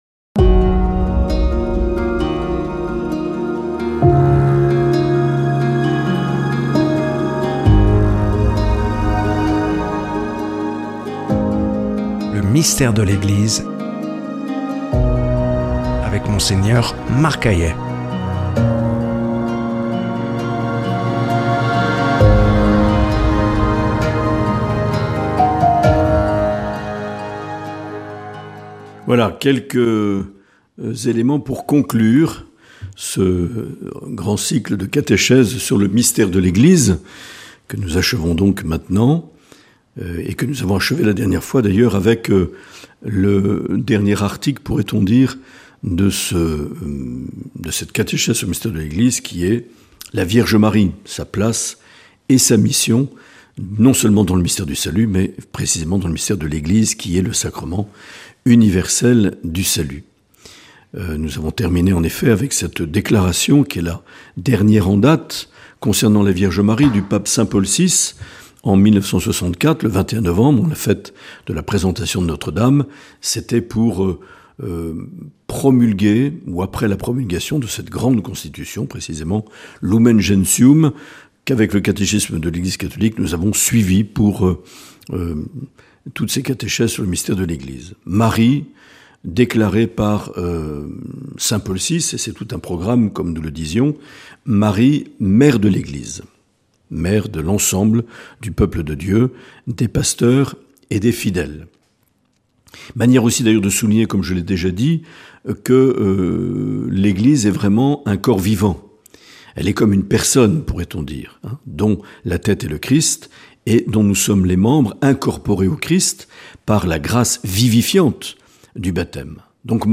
Une émission présentée par
Monseigneur Marc Aillet